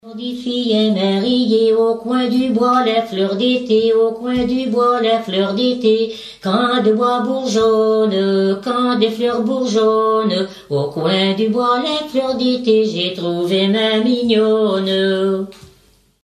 Fonction d'après l'analyste gestuel : à marcher
Genre énumérative
Pièce musicale éditée